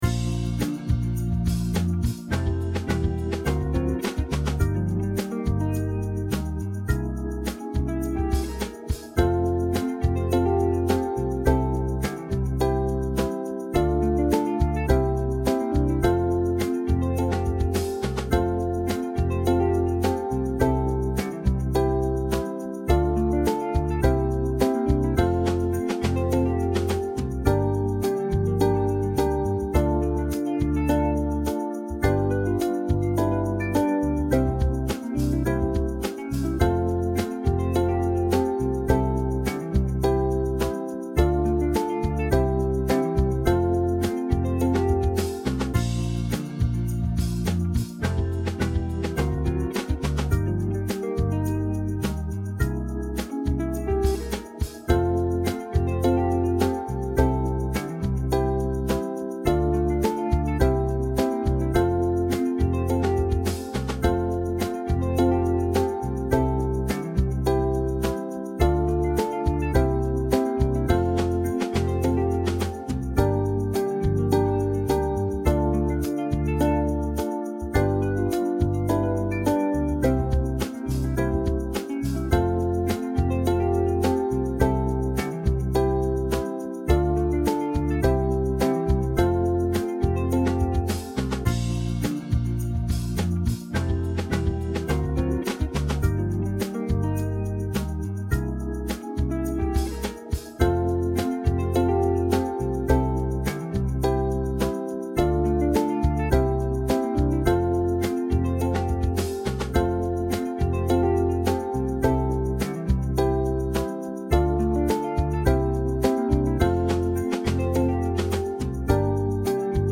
Au Clair de la lune - Spielsatz für UkulelenEnsemble
Gesamtaufnahme,
Au-Clair-de-la-lune-Ensemble.mp3